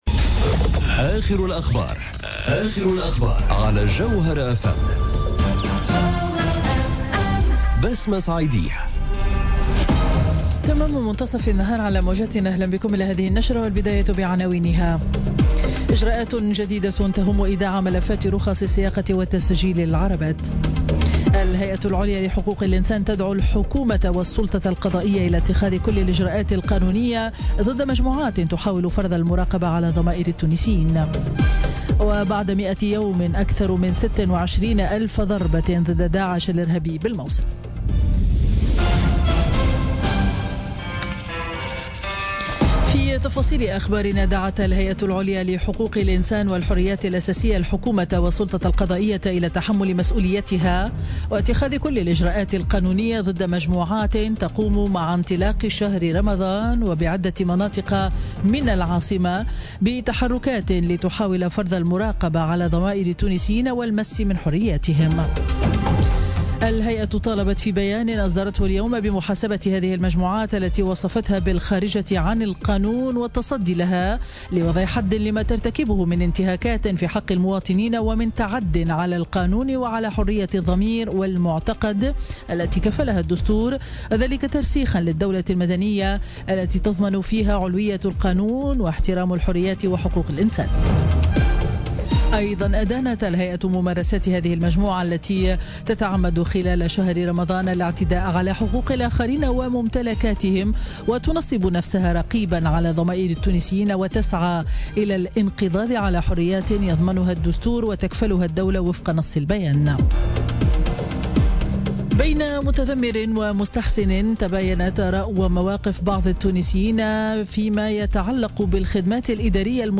نشرة أخبار منتصف النهار ليوم الجمعة 2 جوان 2017